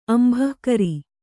♪ ambhahkari